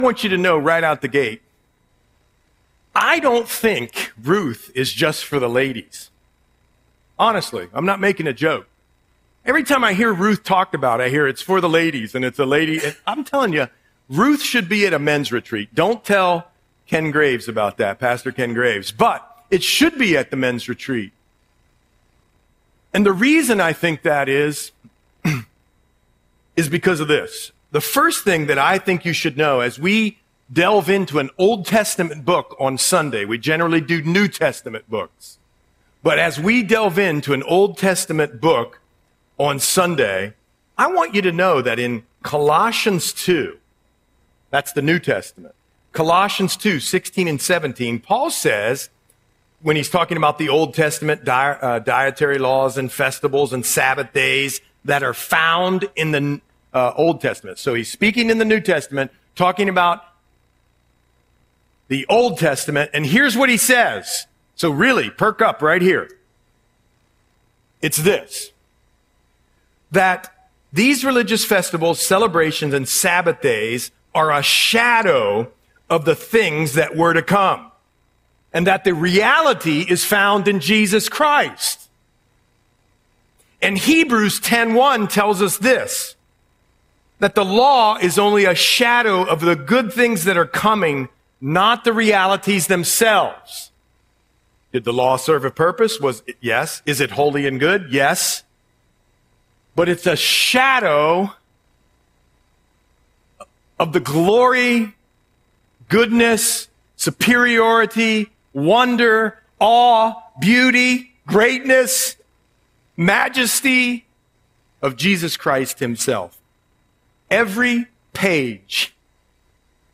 Audio Sermon - August 24, 2025